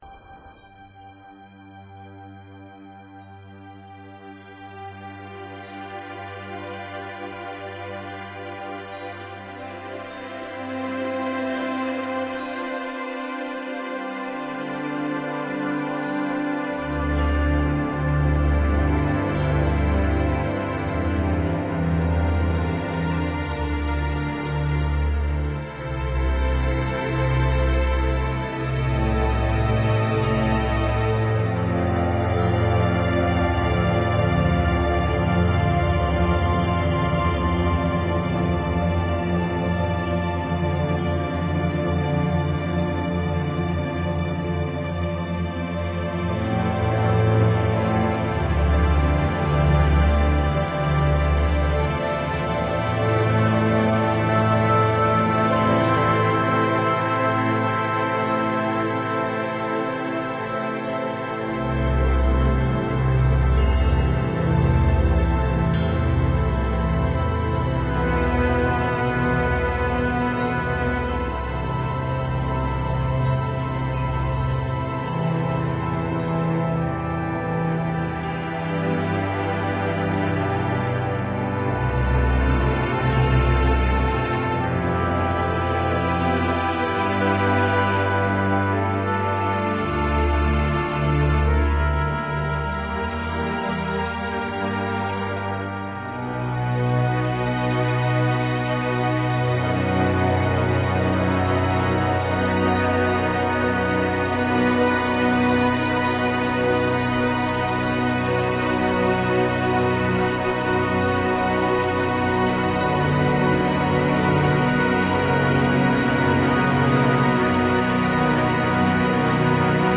Lush symphonic strings. Great for film soundtrack.